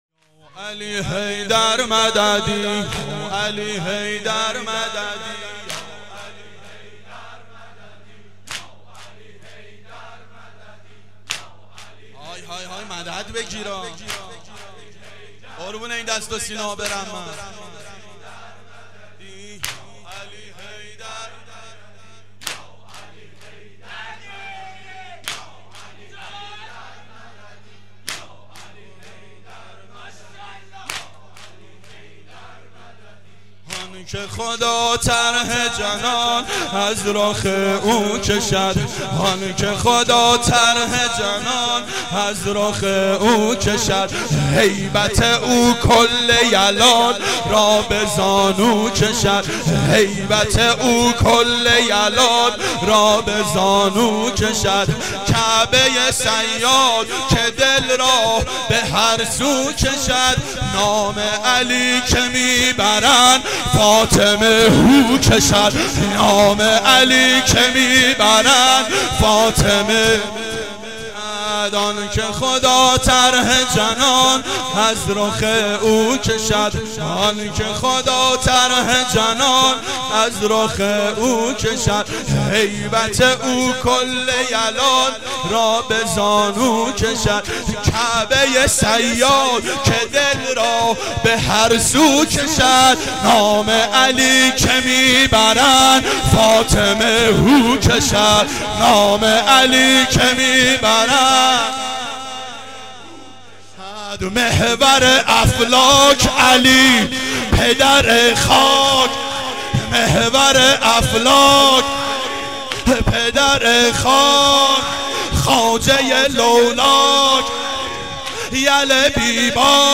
شب سوم محرم 89 گلزار شهدای شهر اژیه